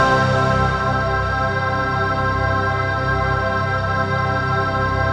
ATMOPAD13 -LR.wav